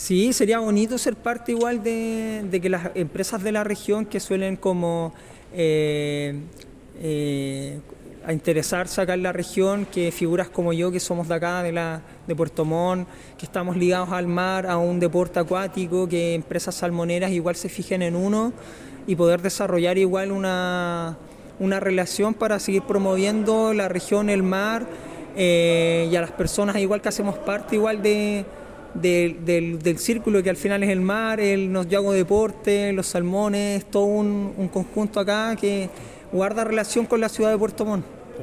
El remero, conversó con Radio Reloncaví, sobre sus logros deportivos en el remo, deporte muy practicado, pero con escaso apoyo de instituciones públicas y privadas.